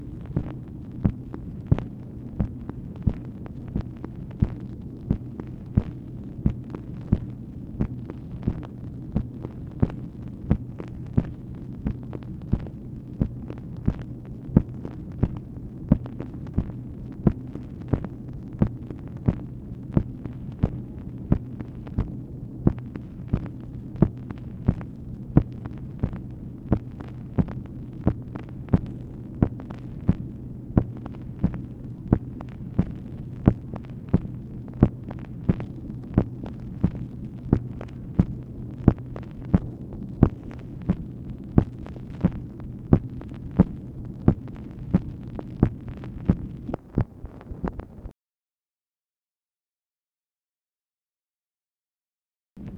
MACHINE NOISE, January 11, 1967
Secret White House Tapes | Lyndon B. Johnson Presidency